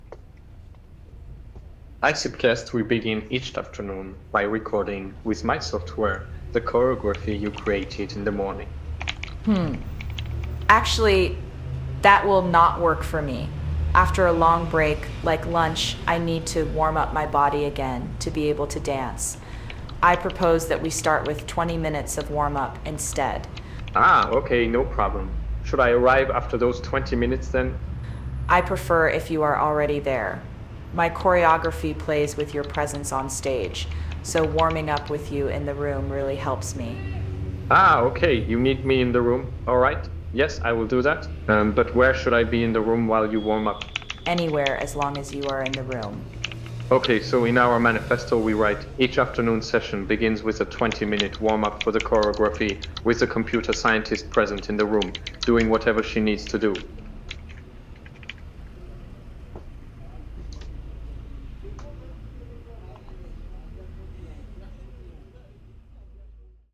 The room smells of fresh coffee, we hear the soft sound of typing as they take notes for their Manifesto.
Thanks to la sonothèque for the background sound.
manifestoDialogueKeyboard.mp3